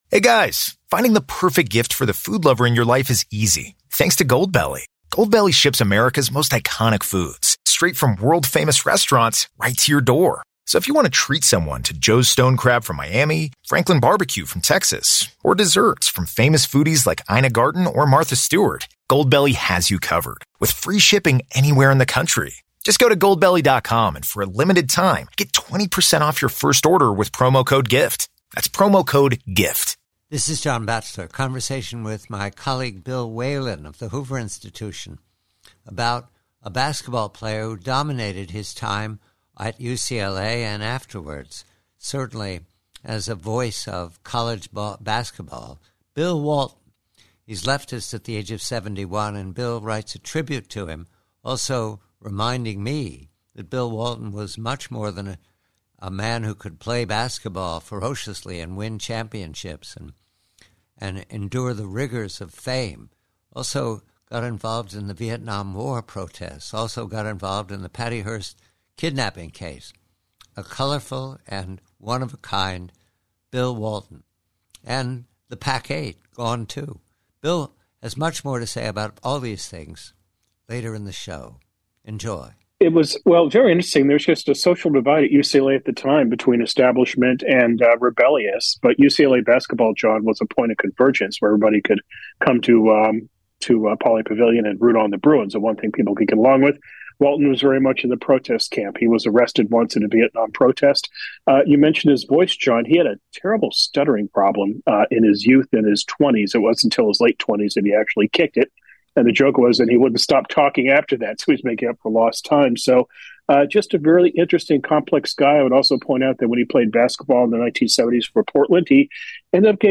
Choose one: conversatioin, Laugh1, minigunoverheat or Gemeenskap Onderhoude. conversatioin